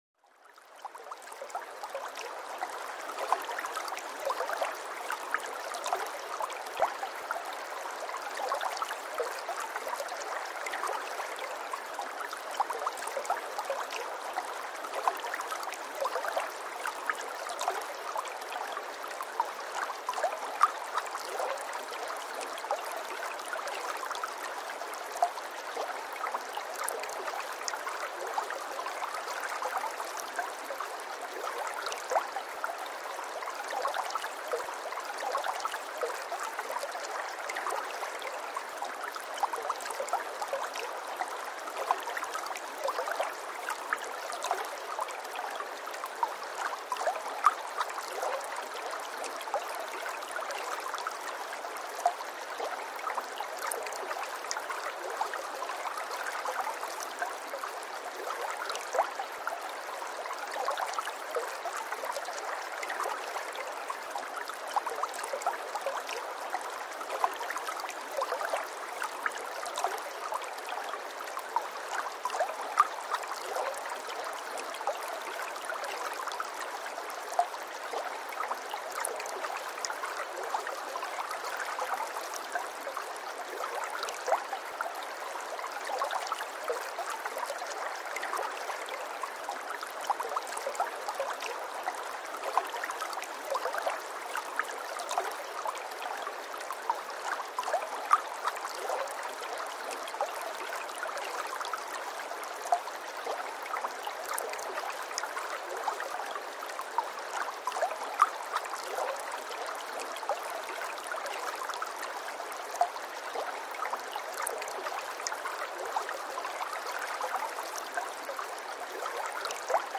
Ambient Stream and Forest Rainfall to Calm the Nervous System for Gentle Concentration for Gentle Concentration
Each episode of Send Me to Sleep features soothing soundscapes and calming melodies, expertly crafted to melt away the day's tension and invite a peaceful night's rest.